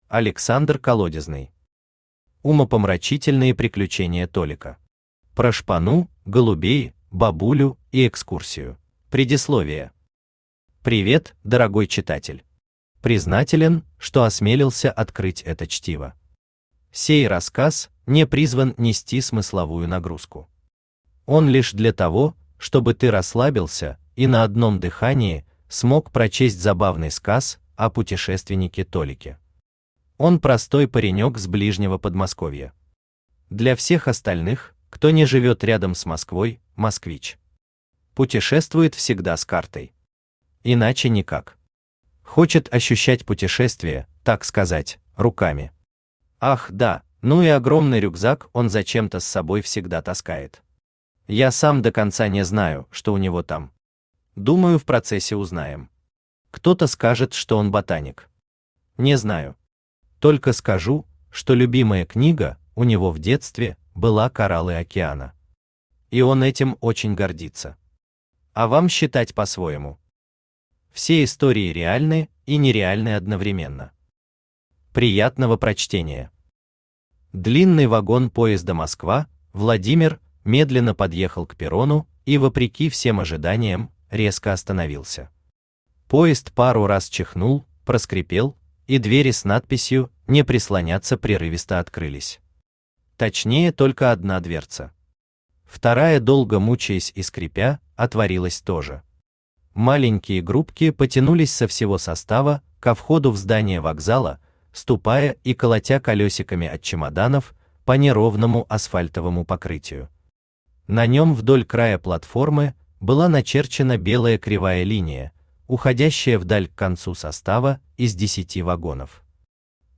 Про шпану, голубей, бабулю и экскурсию Автор Александр Колодезный Читает аудиокнигу Авточтец ЛитРес.